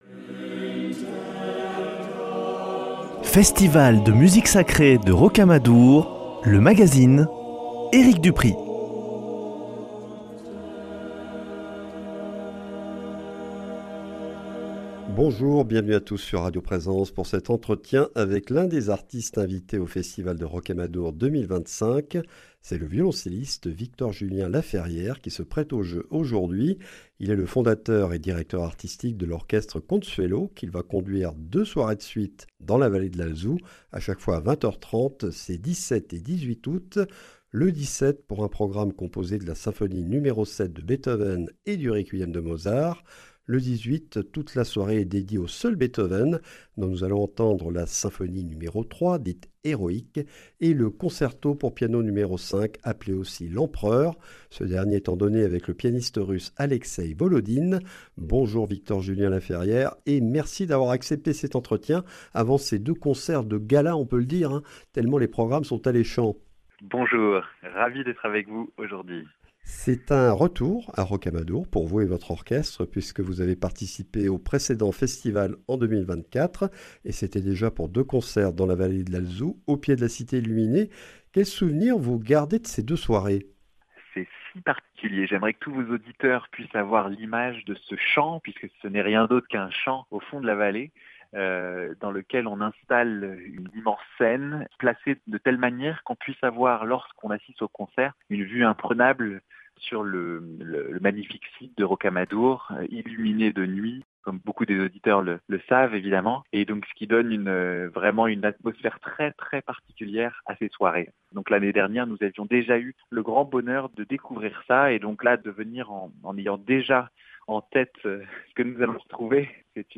Entretien avec le violoncelliste Victor Julien-Lafferrière, fondateur de l’Orchestre Consuelo qu’ill’a dirigé hier dans la 7e Symphonie de Beethoven et le Requiem de Mozart, interprété avec le choeur Orfeon Donostiarra, Nouveau concert ce lundi à 20 h 30 dans la vallée de l’Alzou, pour une Soirée Beethoven dont vont être joués la 3e Symphonie "Héroïque" et le Concerto pour piano n°5 "L’Empereur" où l’orchestre accompagne le pianiste russe Alexei Volodin.